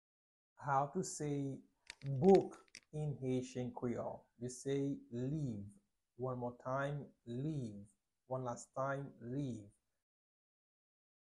Pronunciation:
14.How-to-say-Book-in-haitian-creole-–-Liv-pronunciation-1-1.mp3